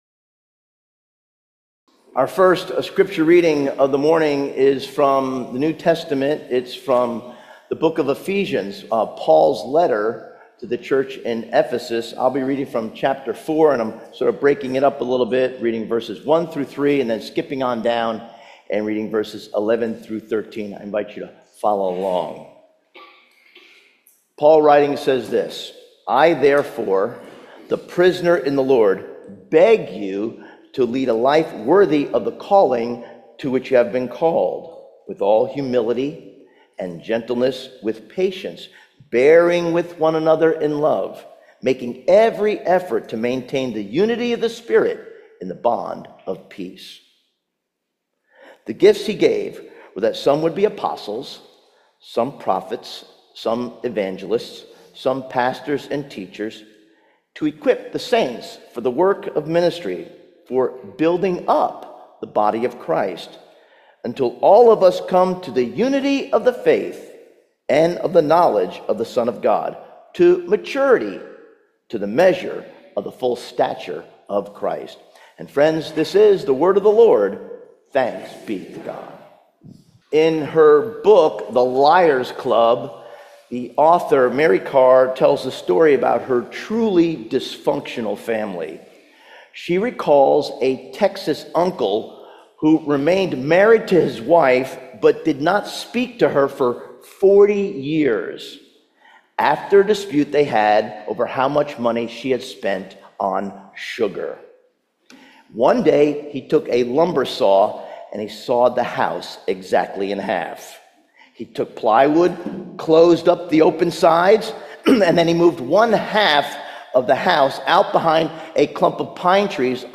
Sermons
Sermons from Chestnut Level Presbyterian Church: Quarryville, PA